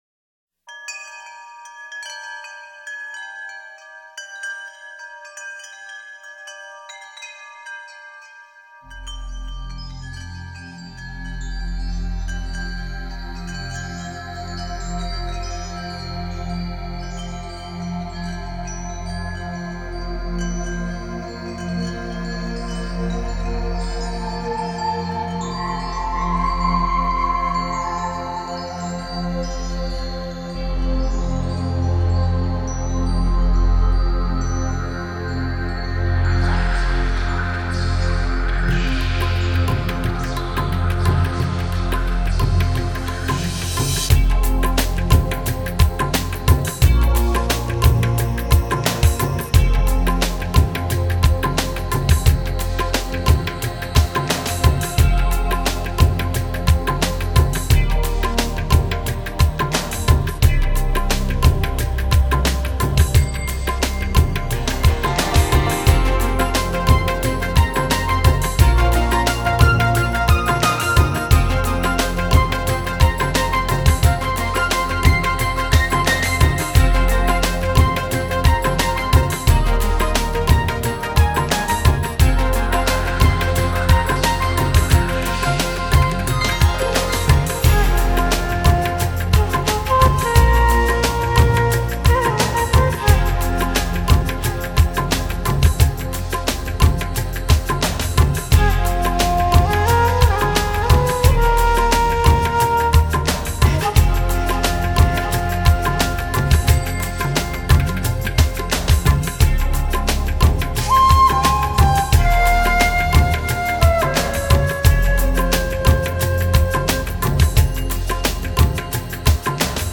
这是一张节奏振奋，旋律轻快的激情音乐！